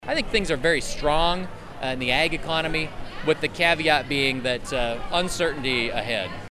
Naig spoke with Radio Iowa at the Iowa State Fair.